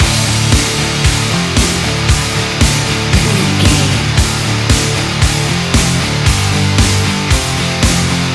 energetic, powerful and aggressive hard rock track
Fast paced
In-crescendo
Ionian/Major
industrial
driving
dark